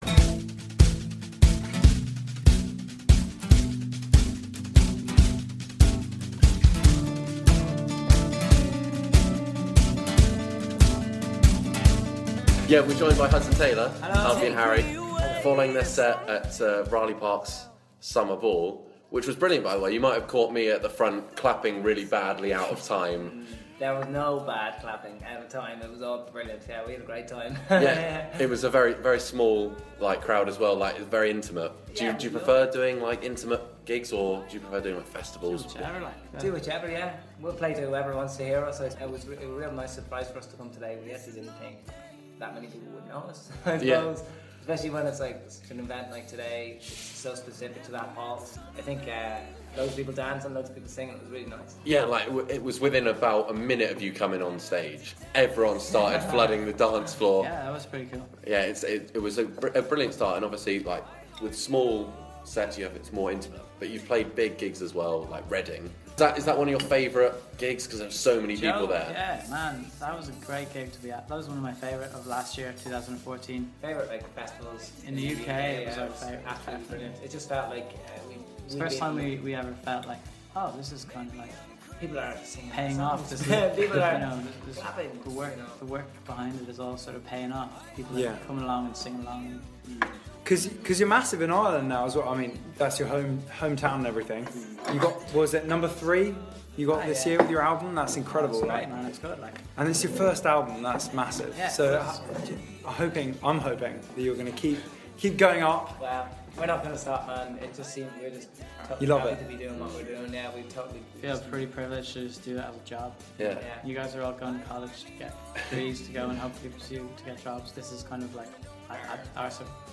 URN Interviews Hudson Taylor